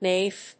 音節na・if 発音記号・読み方
/nɑːíːf(米国英語), nɑɪíːf(英国英語)/